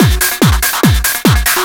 DS 144-BPM B3.wav